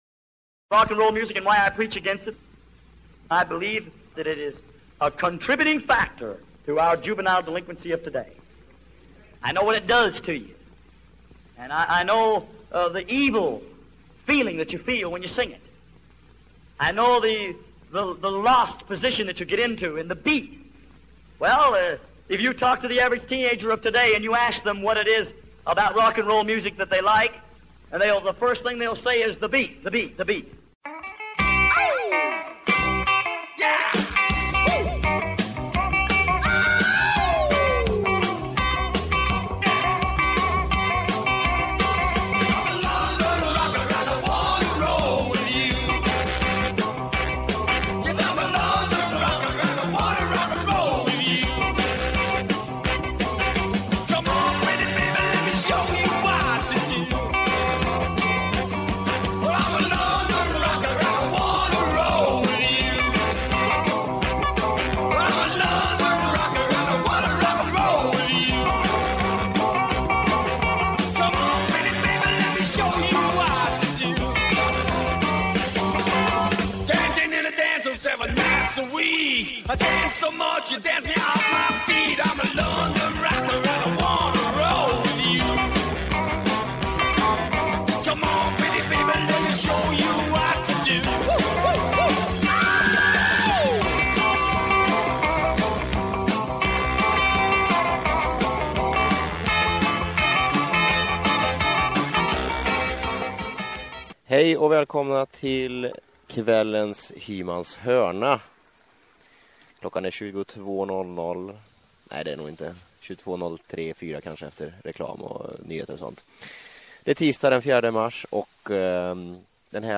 Den här veckan blir det ett specialprogram!